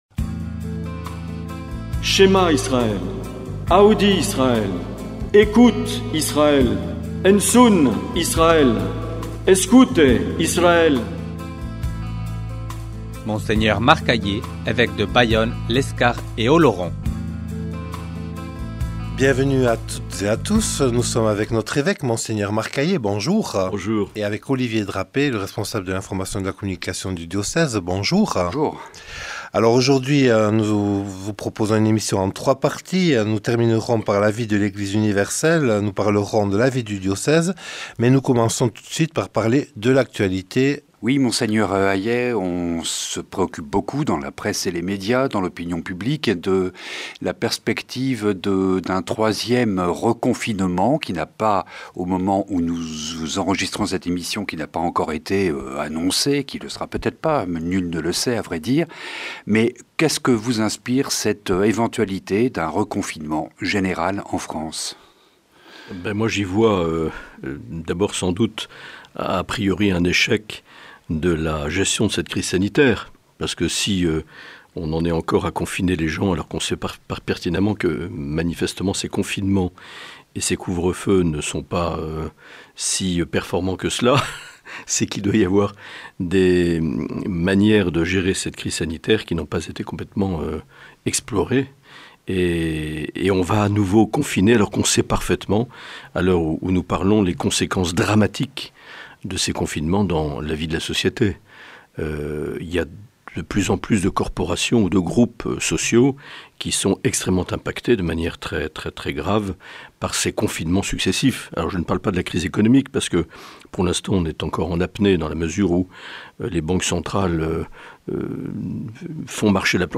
L’entretien avec Mgr Aillet - Février 2021